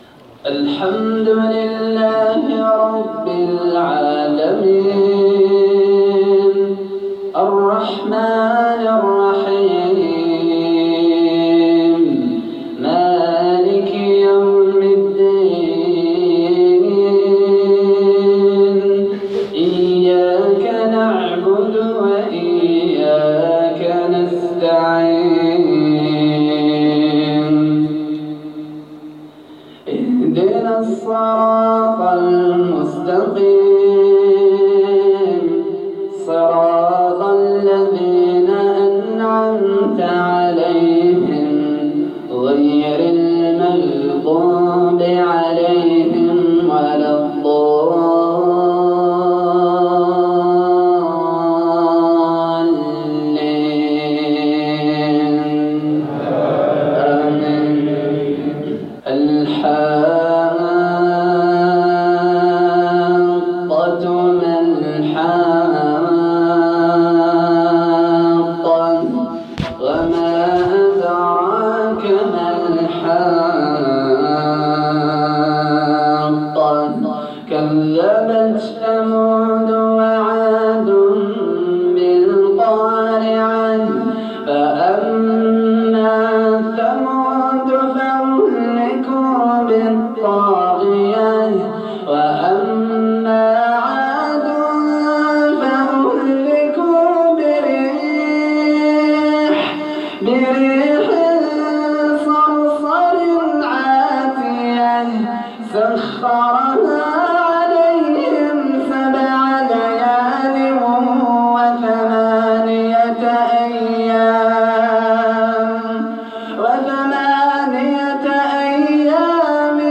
تلاوة خاشعة مبكية تقشعر لها الابدان للقارئ الجزائري